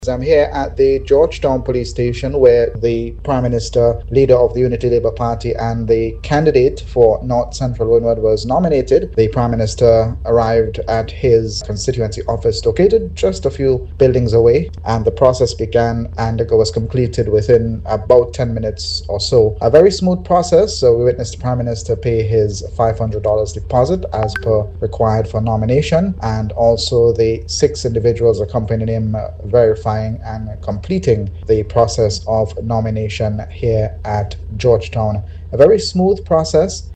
was present at the Georgetown Police Station where Prime Minister Dr. Ralph Gonsalves filed his nomination and sent this Report.